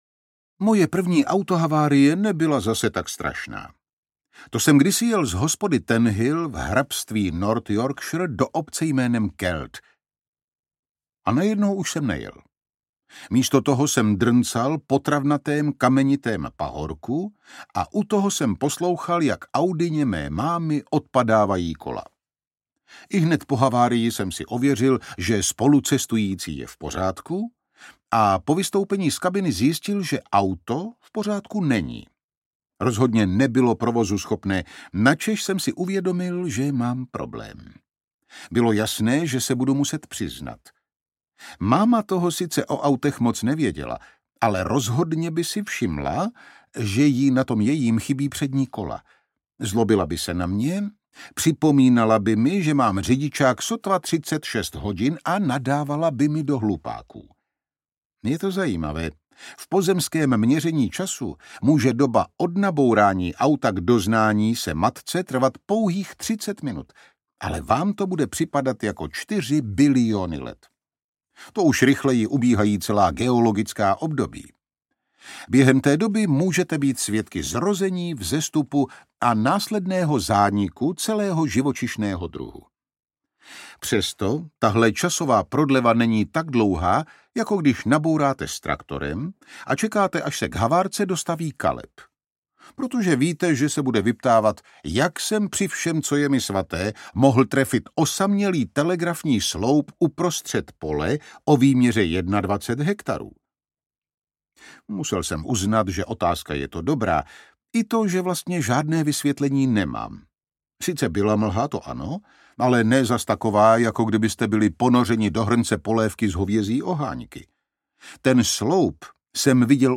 Ukázka z knihy
jeremyho-farma-nejen-zvirat-nez-se-vrati-kravy-audiokniha